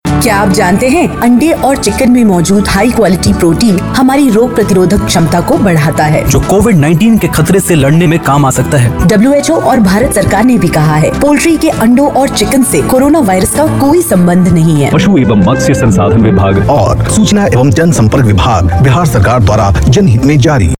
Jingles
Audio Jingle on Corona :: Audio Jingle on Bird Flu ::